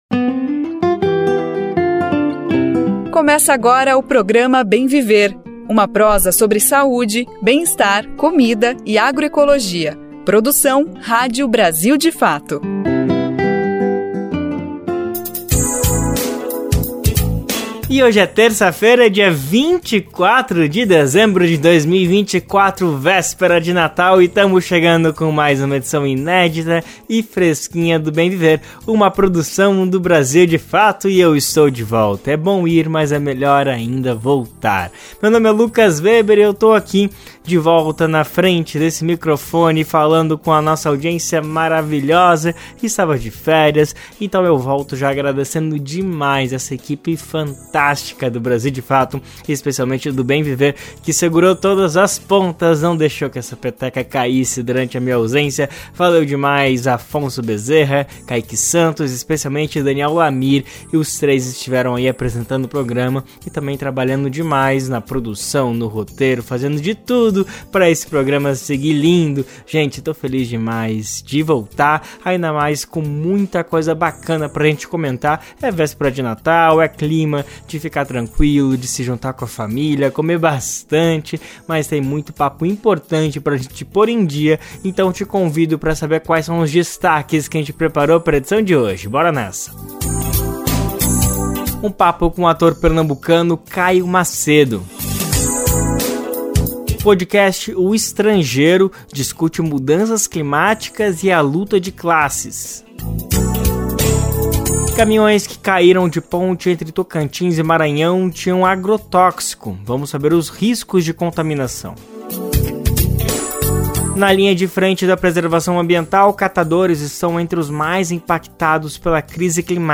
Essa edição do Bem Viver, produzido pelo Brasil de Fato, traz uma conversa